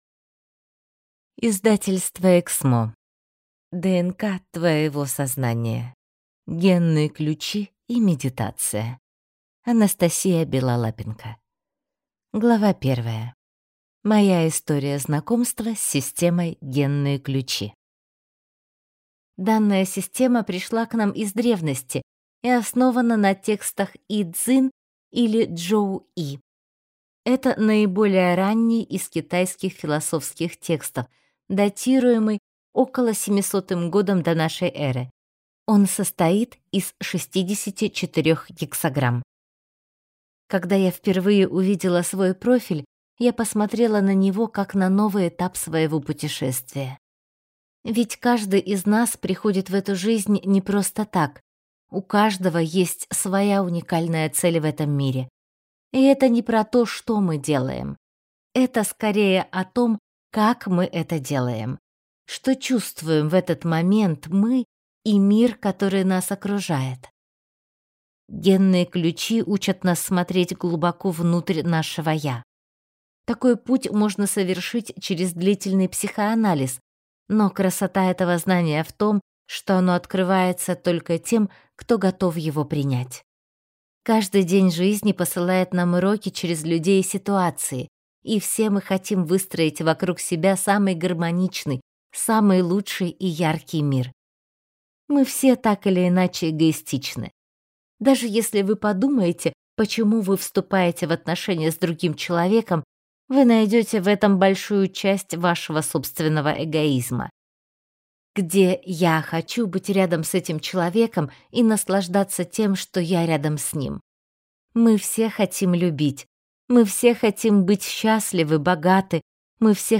Аудиокнига ДНК твоего сознания. Генные ключи и медитация | Библиотека аудиокниг